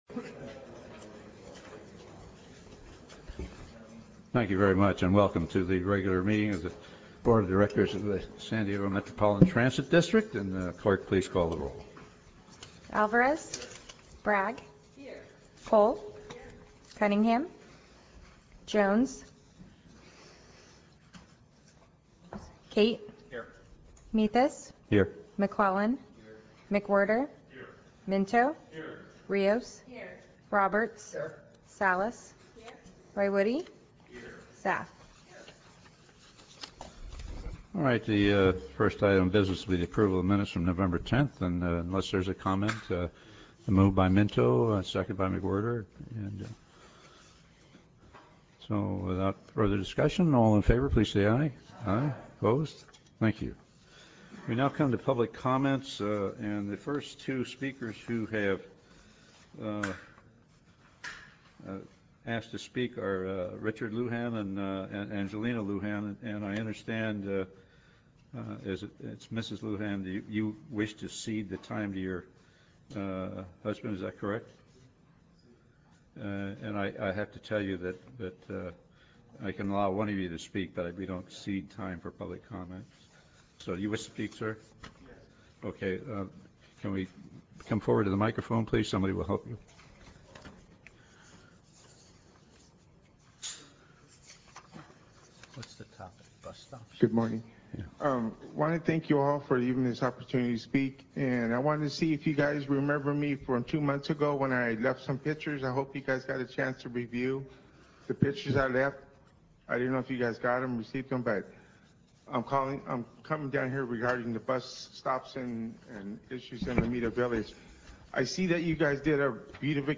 Meeting Type Board Meeting